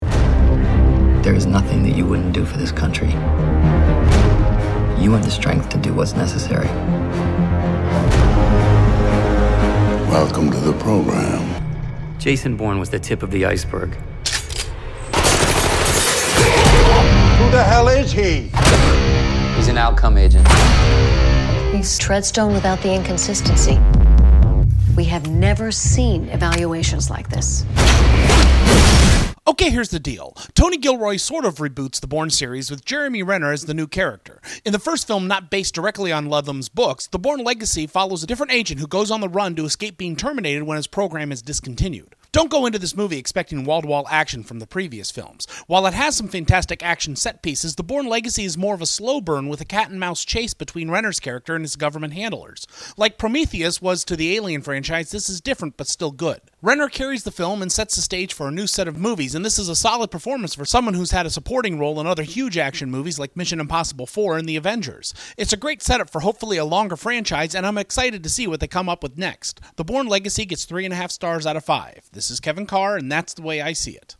Movie Review: ‘The Bourne Legacy’